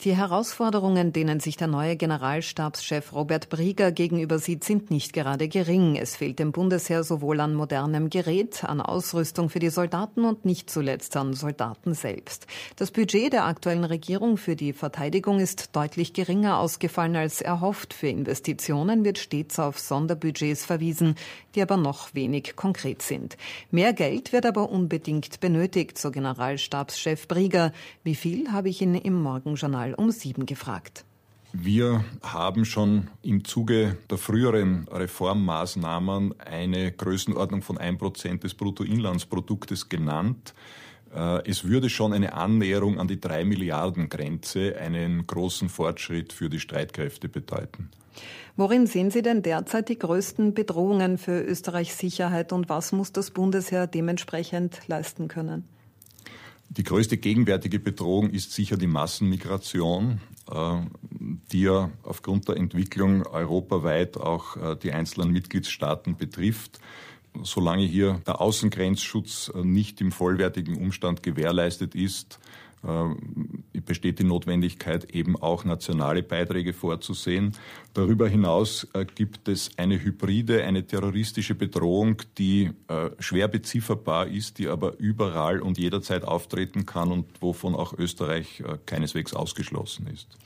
Im Ö1-Morgenjournal am Mittwoch 25.7. wird der Generalstabschef des Bundesheers interviewt: